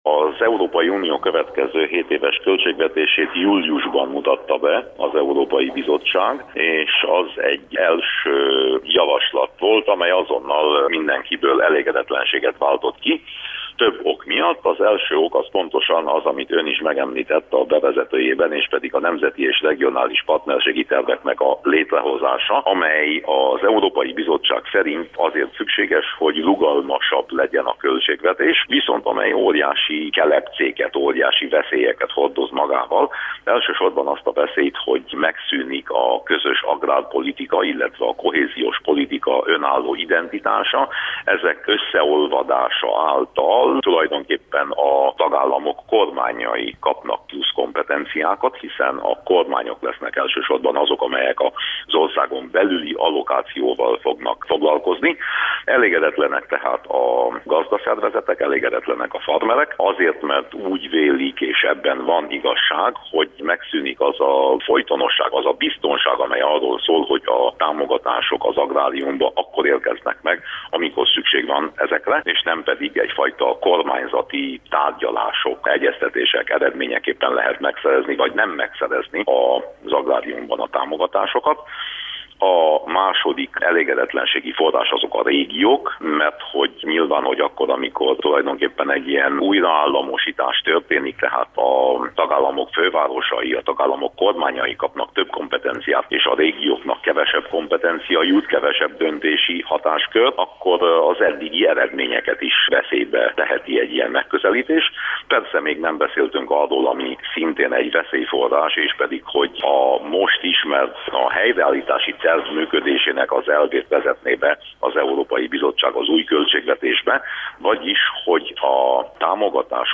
Winkler Gyula EP-képviselő a Kolozsvári Rádiónak adott interjújában emlékeztetett, hogy az EU következő 7 éves költségvetéséről szóló javaslatát júliusban mutatta be az Európai Bizottság.